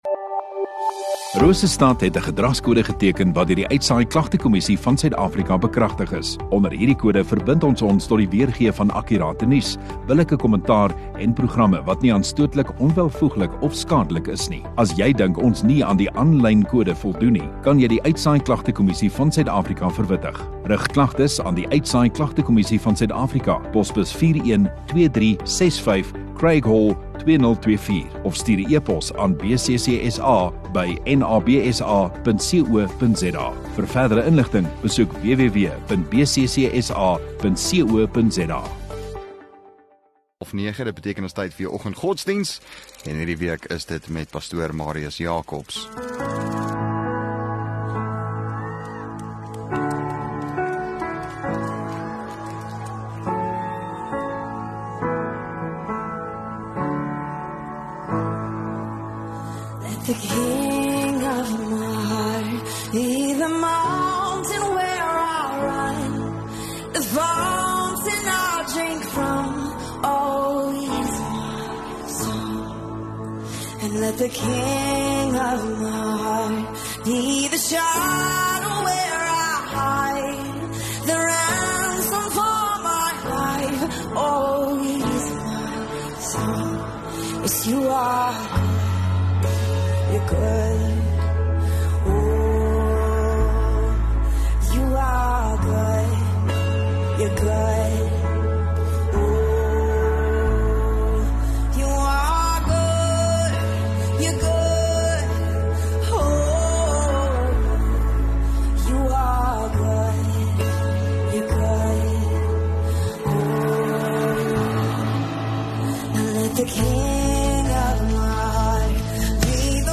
26 Feb Woensdag Oggenddiens